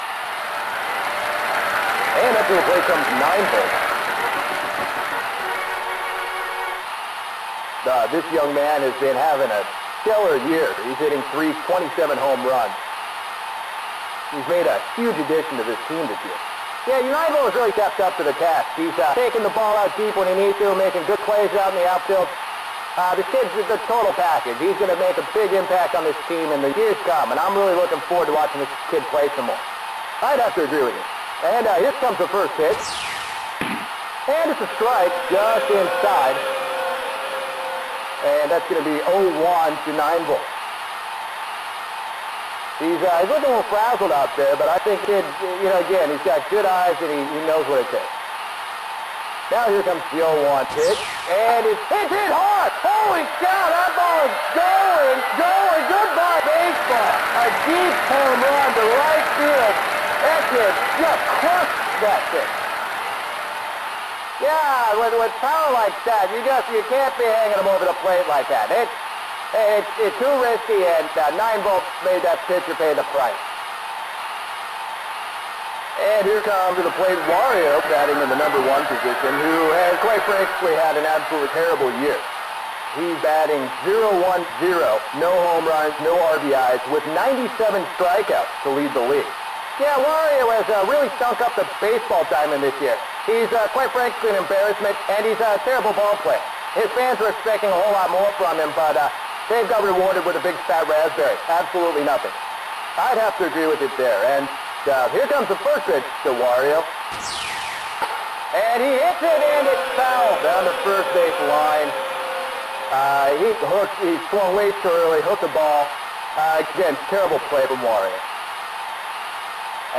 Recorded from WarioWare: Twisted!